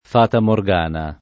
vai all'elenco alfabetico delle voci ingrandisci il carattere 100% rimpicciolisci il carattere stampa invia tramite posta elettronica codividi su Facebook fata morgana [ f # ta mor g# na ] o fata Morgana [ id. ] (raro fatamorgana [ id. ]) s. f.